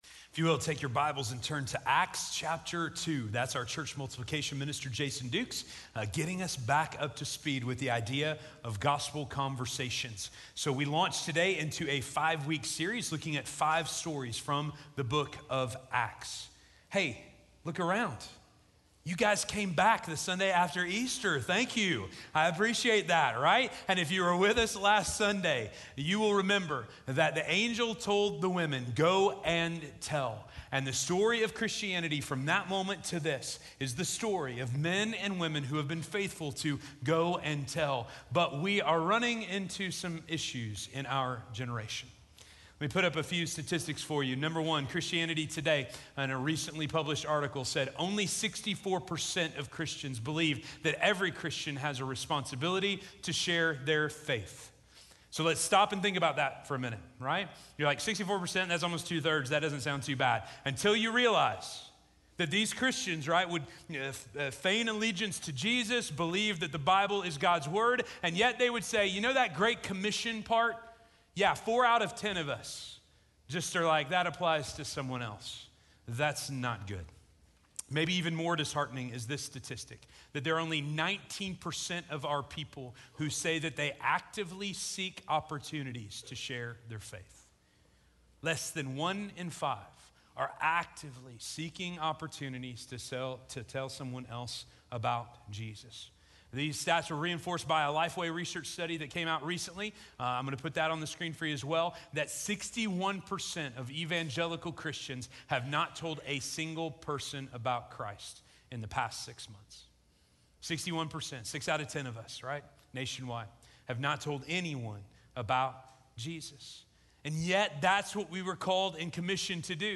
God's Story - Sermon - Station Hill